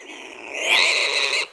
monster / wild_boar / attack_2.wav